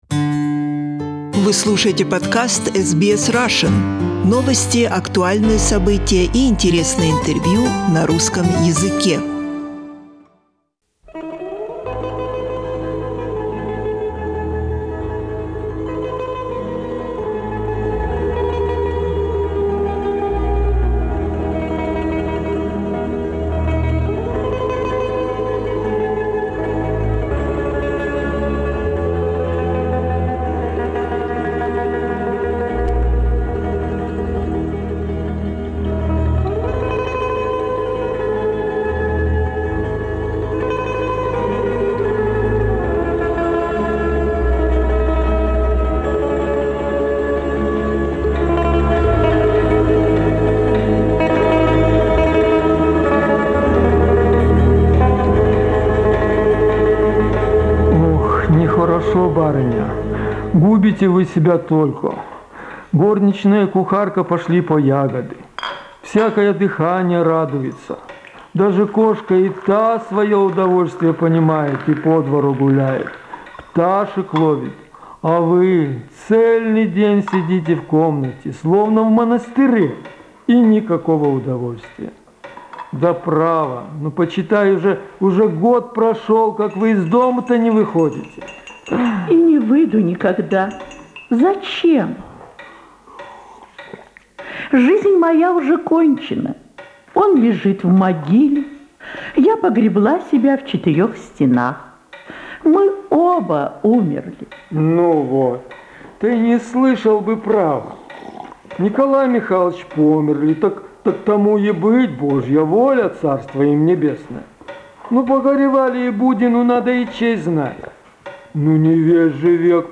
The Proposal. One act comedy
Setting: A drawing-room in CHUBUKOV'S house.